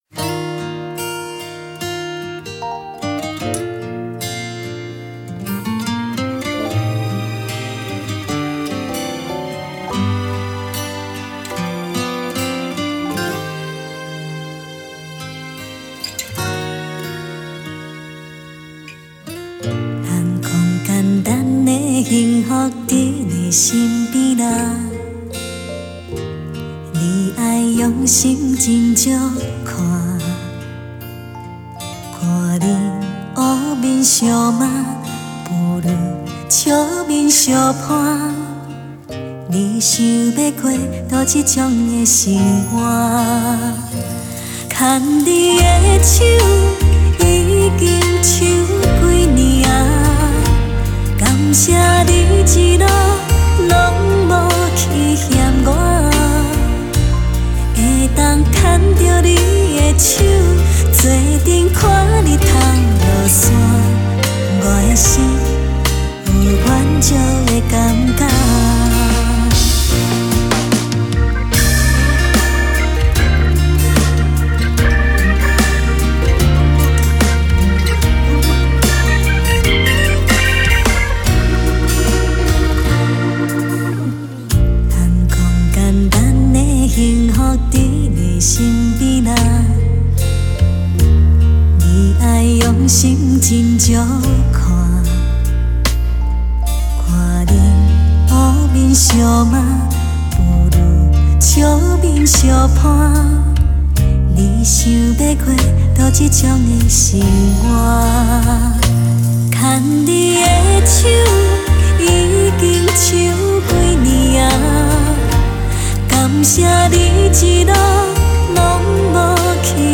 流露真情的歌声　幸福的女人
唱出真情的心声，有着幸福的悸动。